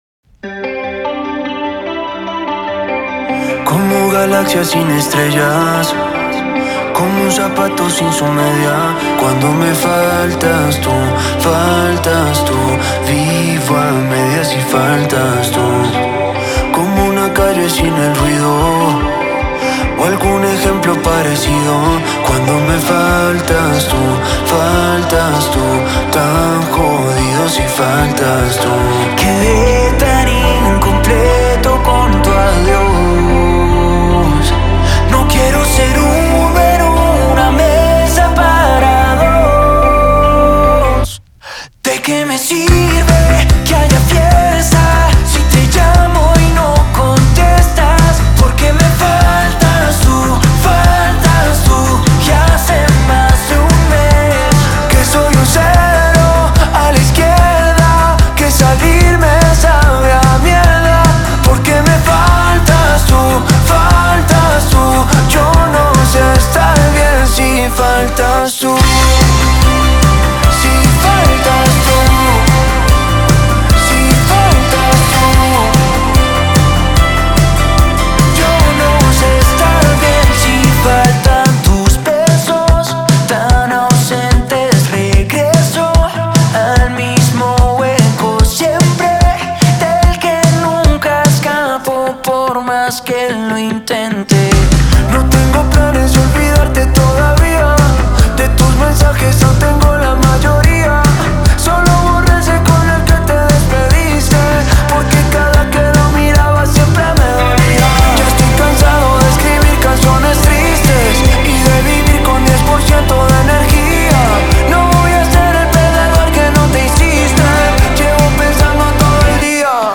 Попытка- не пытка, попробую) В продолжение темы латино-поп